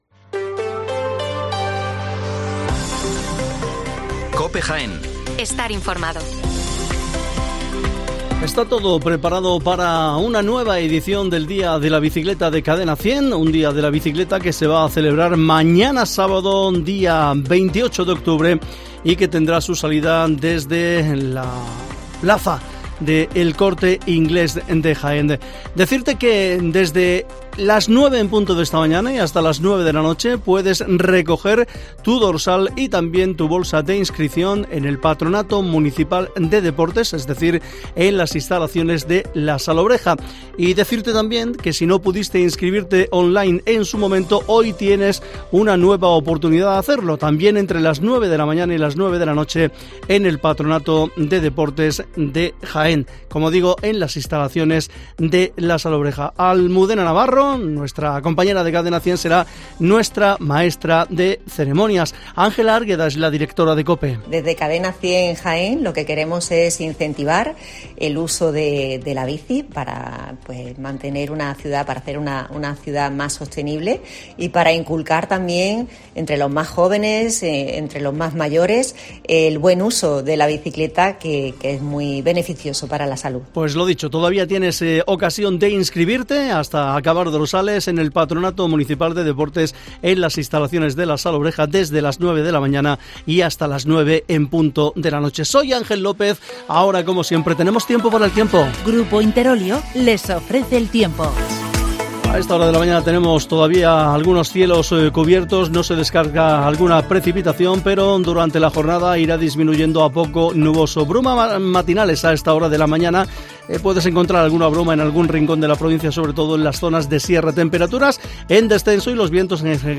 Las noticias de la mañana